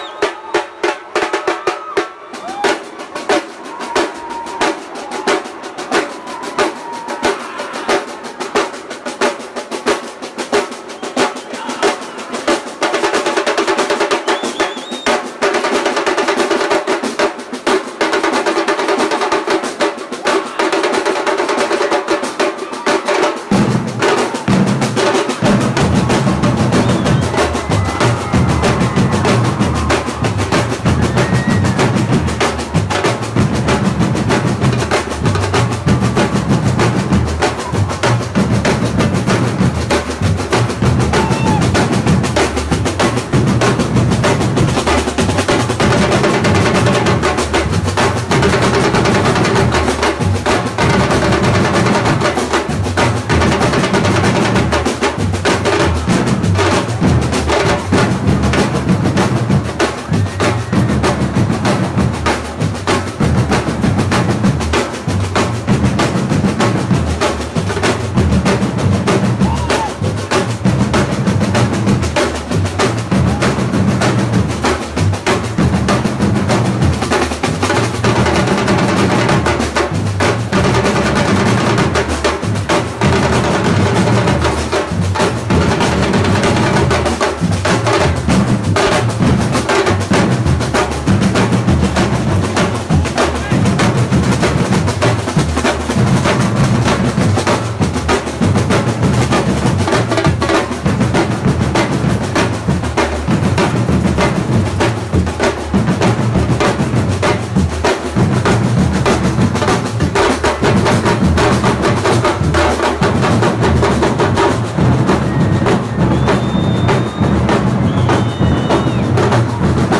Drums.mp3